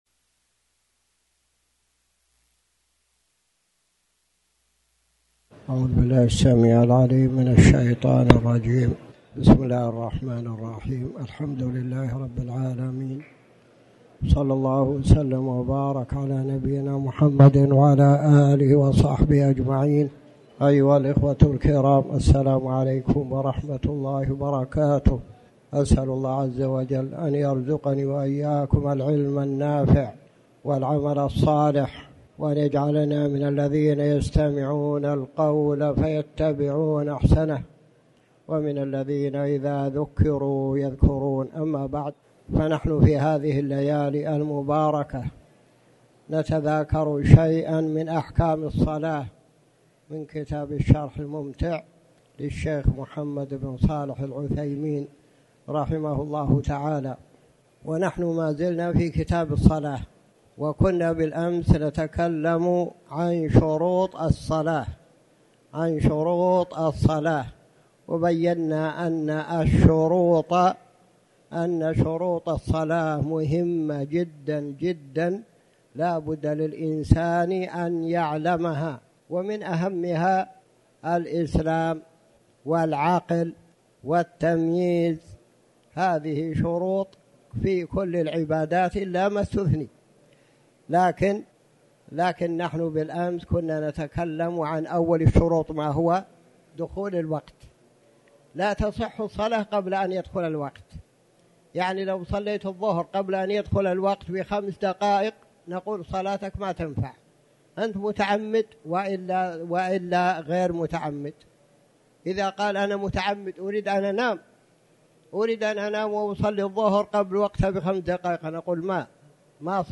تاريخ النشر ٢١ صفر ١٤٤٠ هـ المكان: المسجد الحرام الشيخ